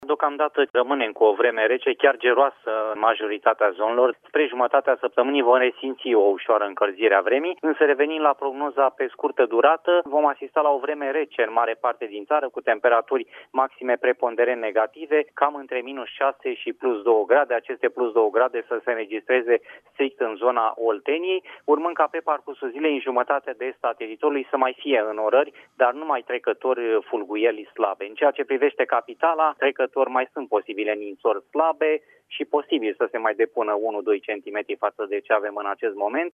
într-o intervenţie la Radio România Actualităţi